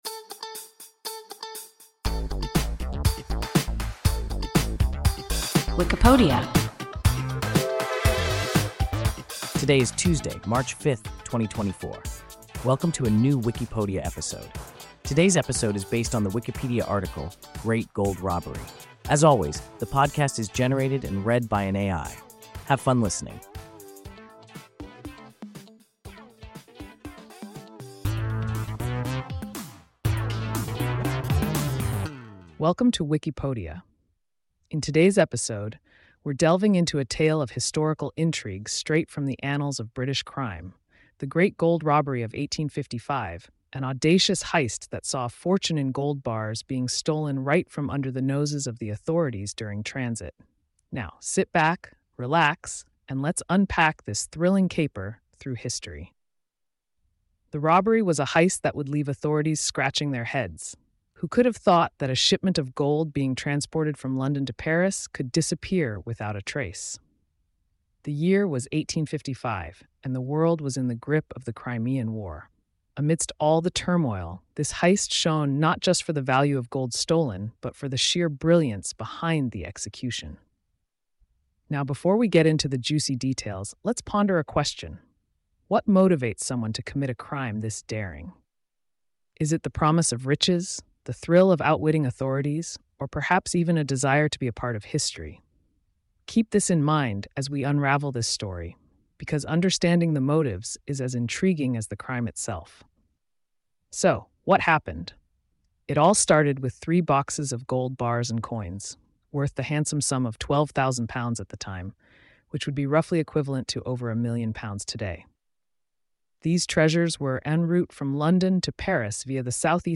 Great Gold Robbery – WIKIPODIA – ein KI Podcast